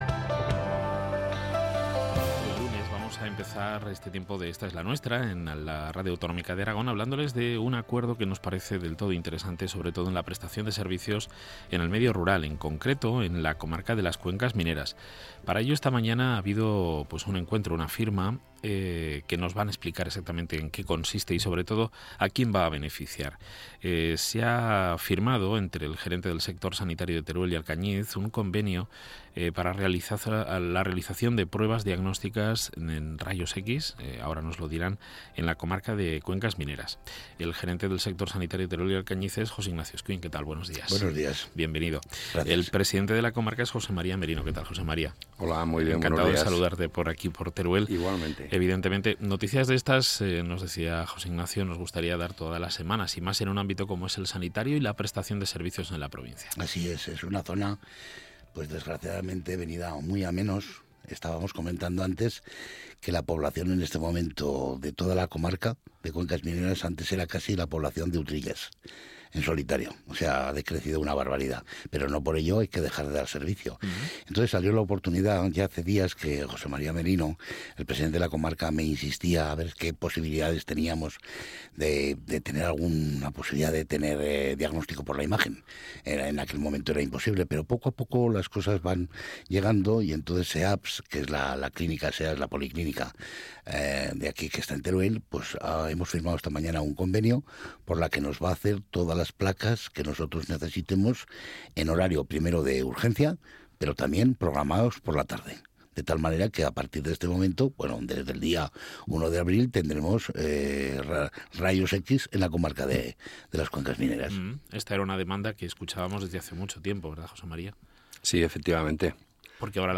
Reportaje Diario de Teruel Programa de radio «Ésta es la Nuestra» Compartir...